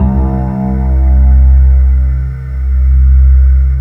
Index of /90_sSampleCDs/USB Soundscan vol.28 - Choir Acoustic & Synth [AKAI] 1CD/Partition C/13-MARJOLIE